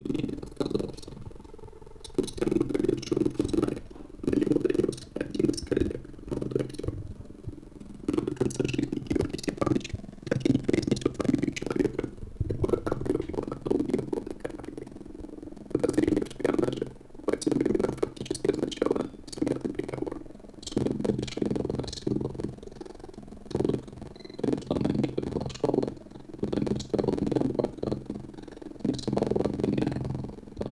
"Булькающий" звук. RME FF 802 (аудио пример)
Дорогие знатоки, помогите определить причину звуковых искажений. Вчера смотрел Ютуб со включённым интерфейсом RME Fireface 802 и вдруг посреди фильма вот такой звук напоминающий бульканье (см. аудио пример) Обновил Ютуб, на некоторое время пропало "булькание".